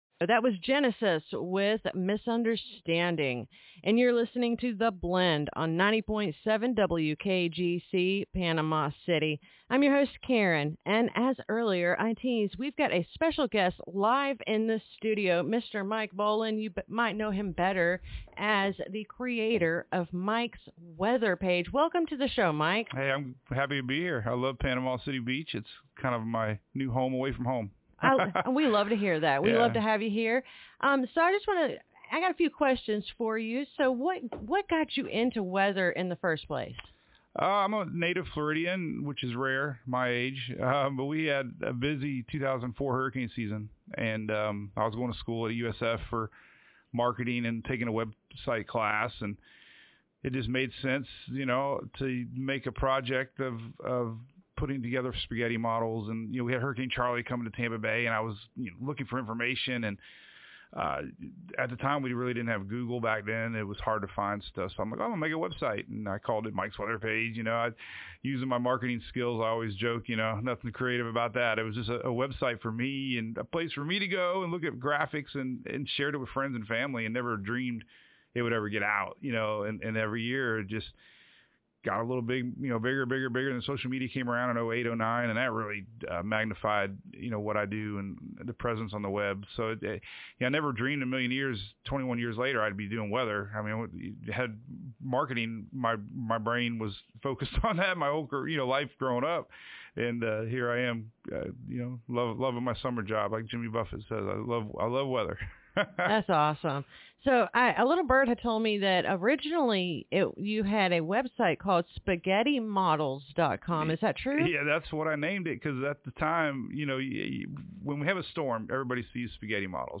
WKGC Studio – Have you ever seen or heard the term “spaghetti models” in Hurricane season?